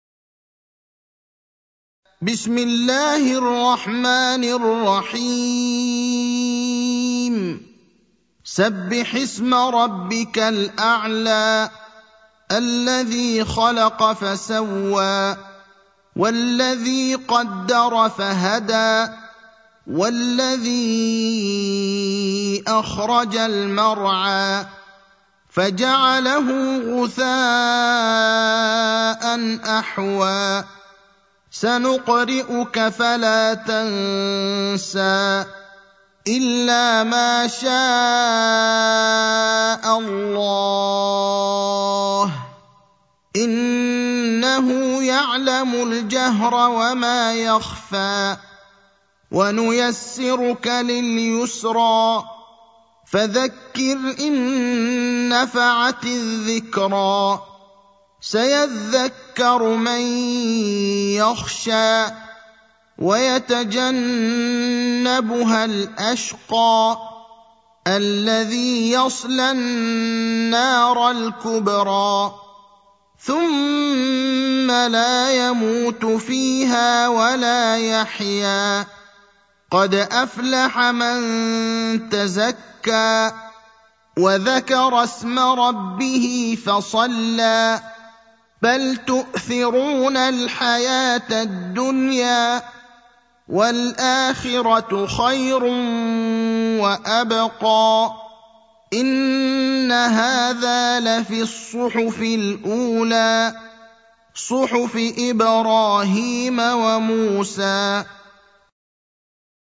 المكان: المسجد النبوي الشيخ: فضيلة الشيخ إبراهيم الأخضر فضيلة الشيخ إبراهيم الأخضر الأعلى (87)